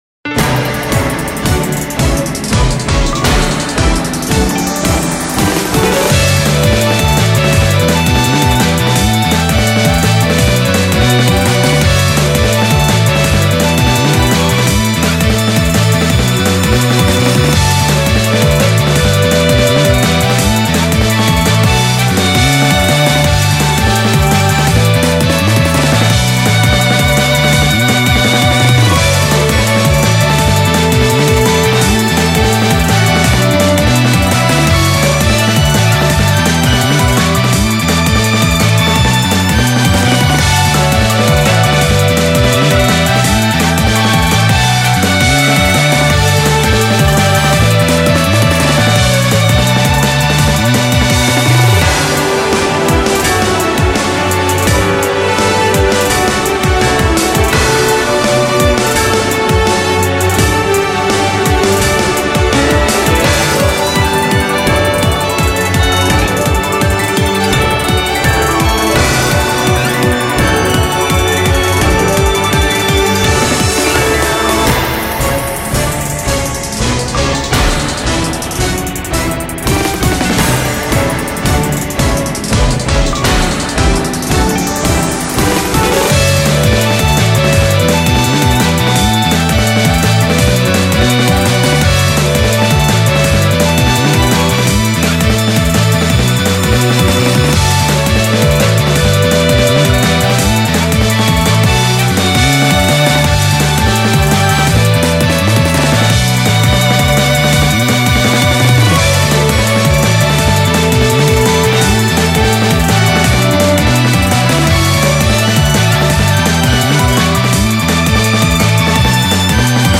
ジャンルロック、ポップ
BPM１６８
使用楽器ヴァイオリン、ブラスセクション
解説ヴァイオリンの旋律が美しい戦闘曲フリーBGMです。
“天上から降り注ぐ神々しい美の波動”をイメージして制作いたしました。